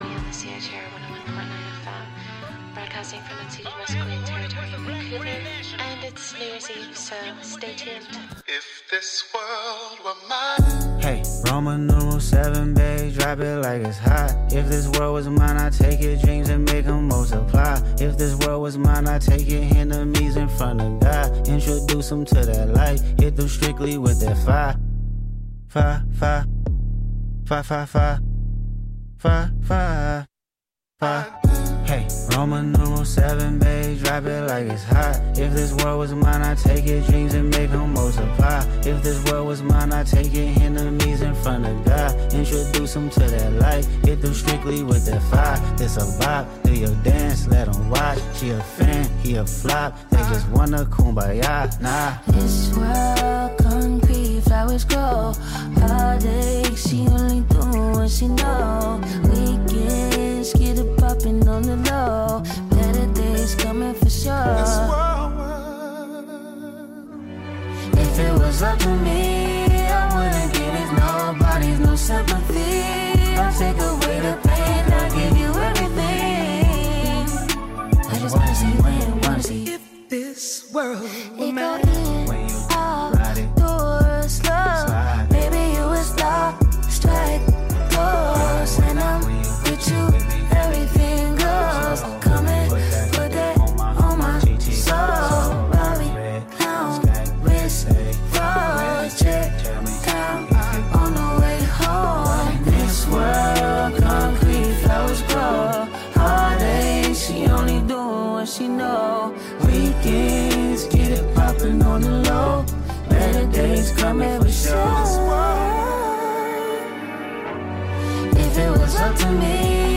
Some new and old world emo music on the eve of 2025.